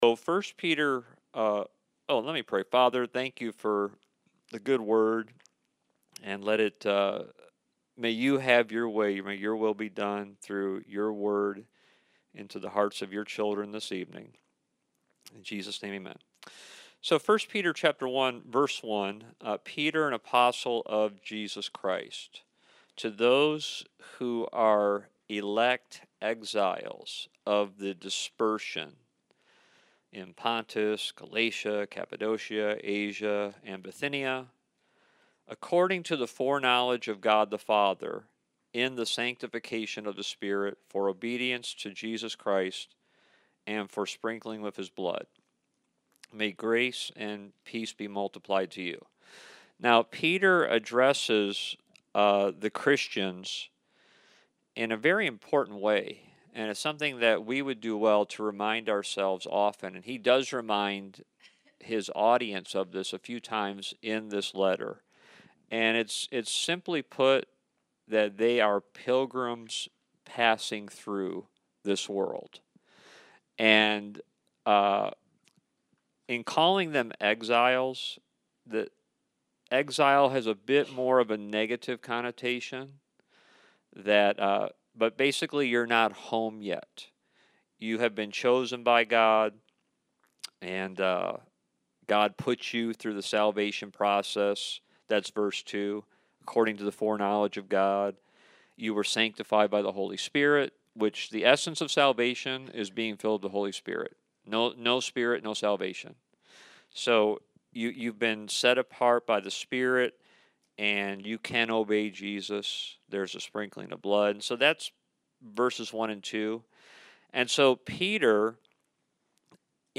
Service Type: Wednesday Night